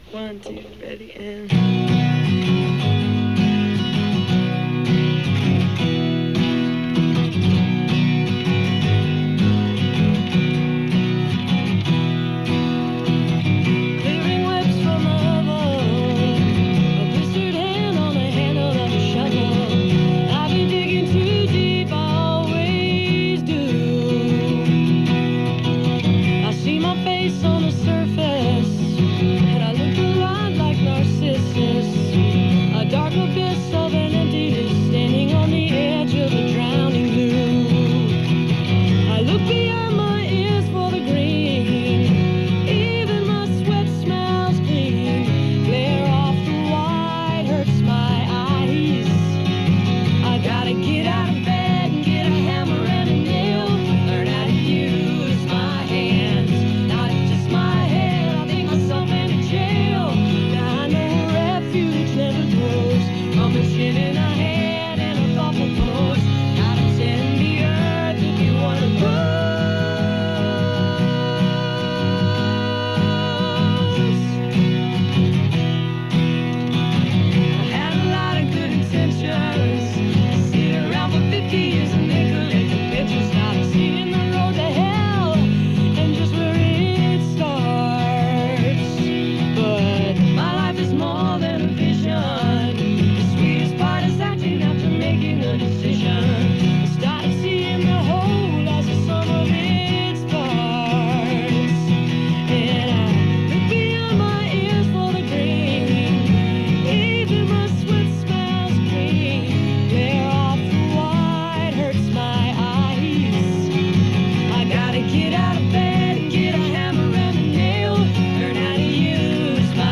(acoustic duo show)
live in the studio